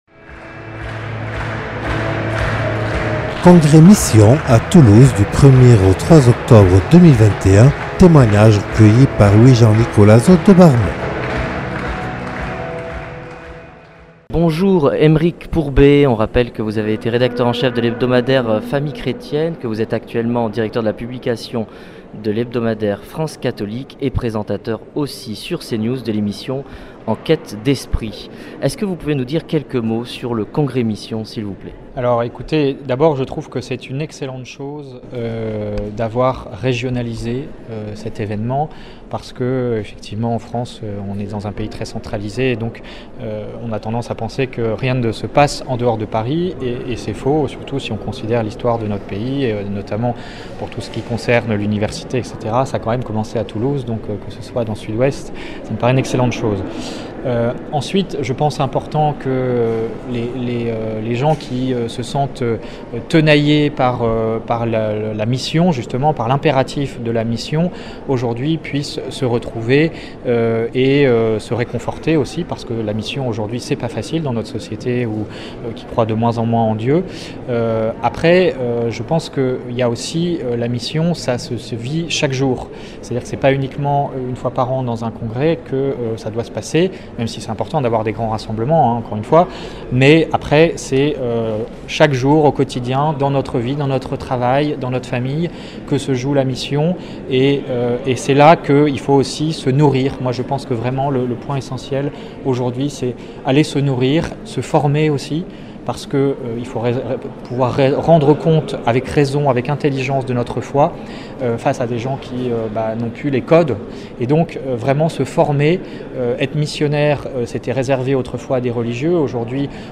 Reportage réalisé au congrès Mission à Toulouse du 1er au 3 octobre 2021.